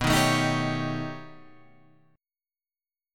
B Augmented 9th